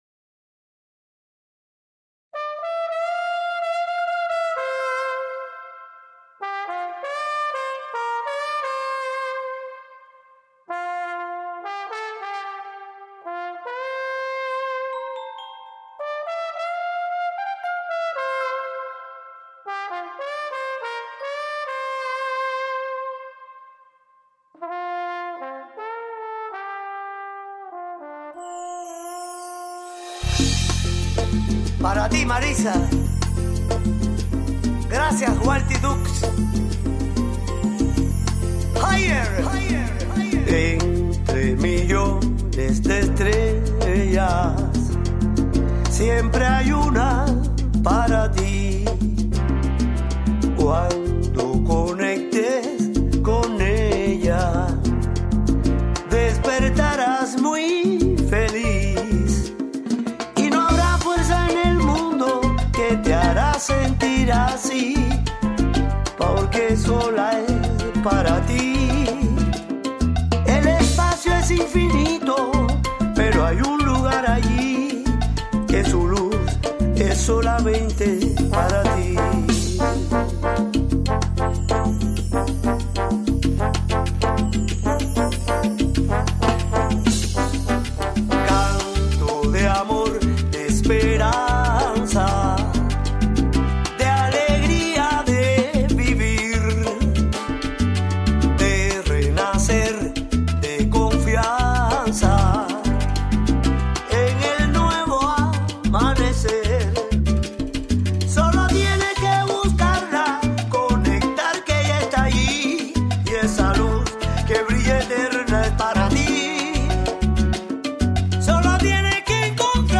Salsa version from Cuba